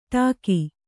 ♪ ṭāki